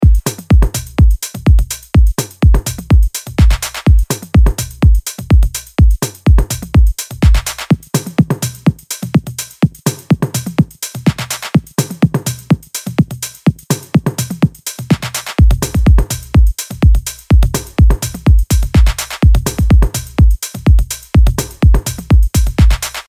ダンスフロアに向けたパンチの効いた4つ打ちビートとグルーヴ
フロアを熱狂させる4つ打ちのキック、パンチのあるクラップ、シンコペーションの効いたハイハット、温かみを持つ質感のパーカッションで、あなたのトラックを躍動させましょう。
XO Expansion House プリセットデモ